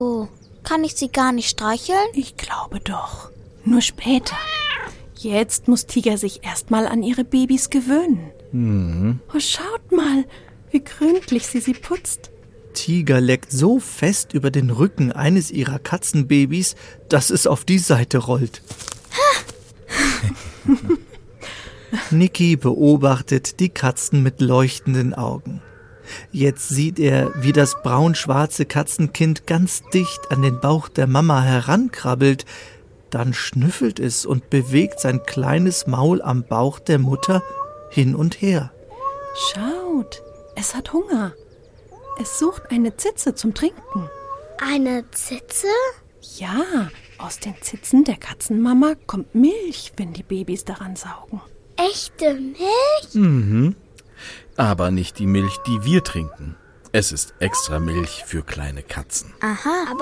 (Hörbuch/Hörspiel - CD)
Hörspiele